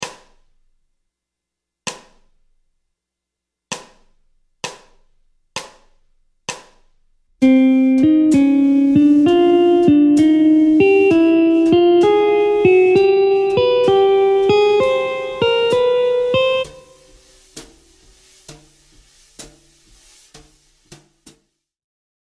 Gamme mineure harmonique ( I – mode mineur harmonique)
{1 2 b3 4 5 b6 7∆}
Montée de gamme
Gamme-bop-asc-Pos-31-C-min-harm.mp3